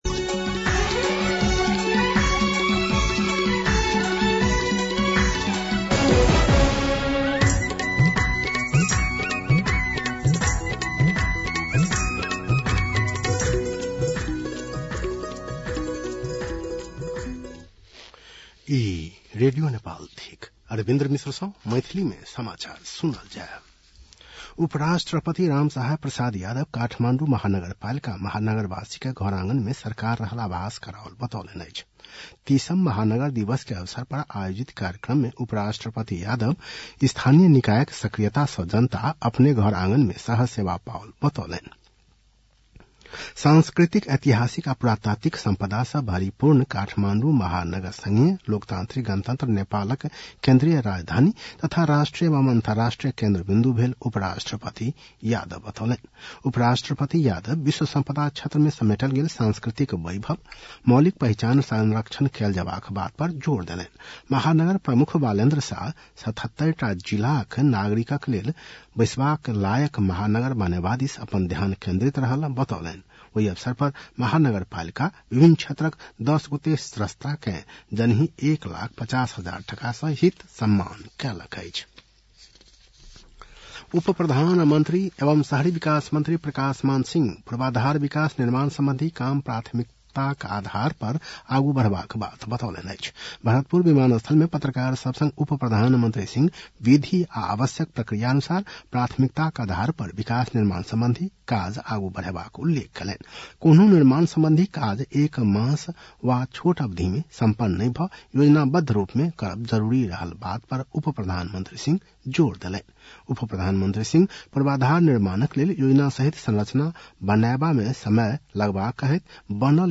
मैथिली भाषामा समाचार : ३० मंसिर , २०८१
Maithali-News-8-29.mp3